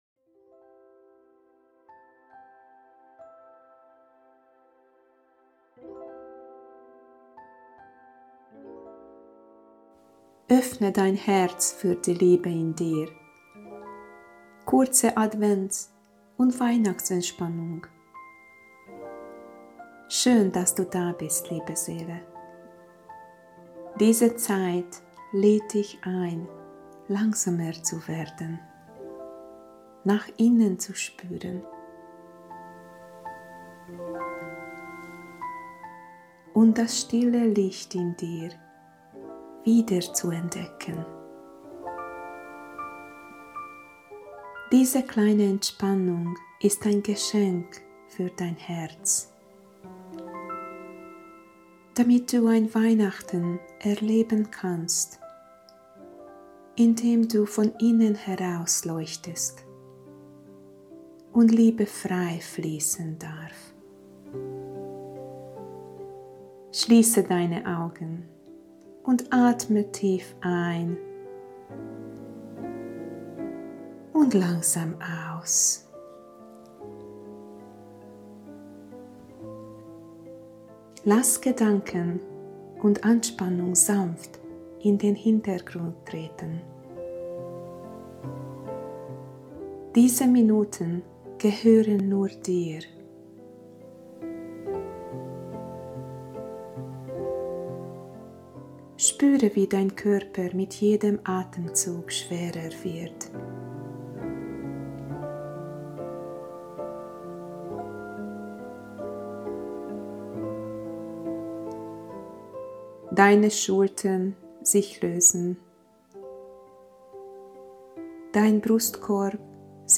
Meine Aufnahmen verbinden autogenes Training mit intuitiver Führung.
Öffne dein Herz für die Liebe in dir – Geführte Advents- und Weihnachtsentspannung
Nur meine Stimme begleitet dich sanft, während Körper und Geist entspannen und du deine innere Ruhe wiederfindest.